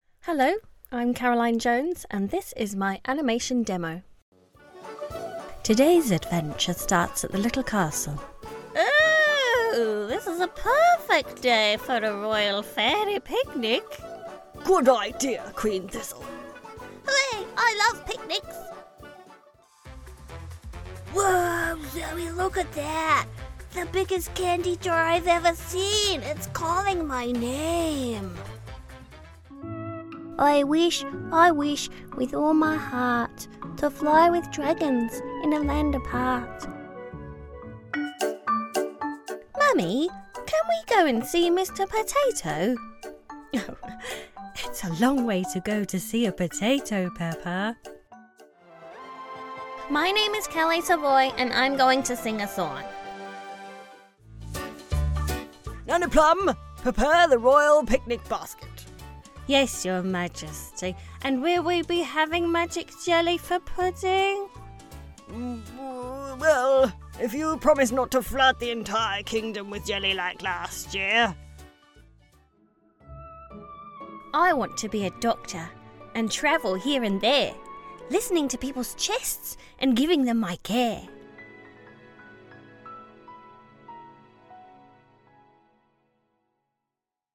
Character, Cartoon and Animation Voice Overs
Yng Adult (18-29) | Adult (30-50)